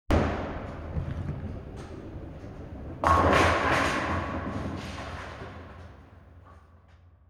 Bowling Strike.m4a